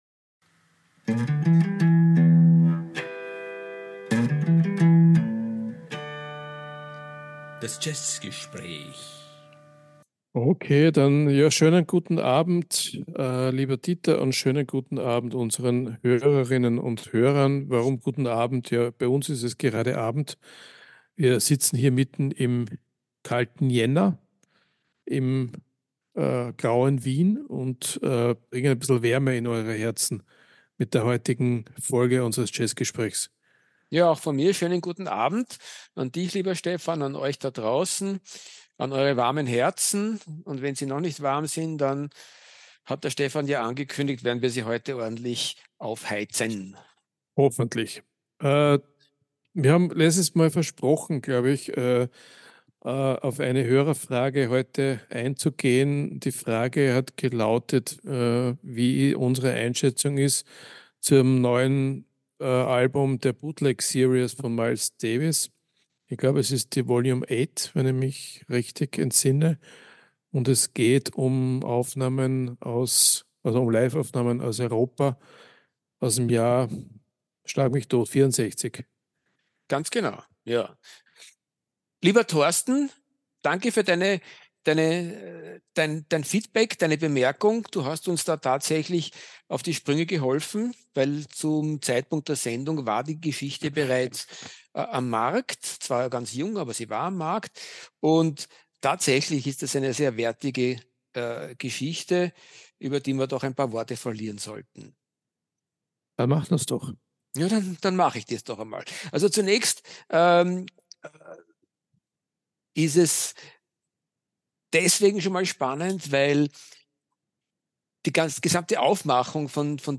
Das ist natürlich ein Jazzgespräch über den großen Duke Ellington und sein unermessliches Schaffen im US-amerikanischen Jazz des 20. Jahrhunderts.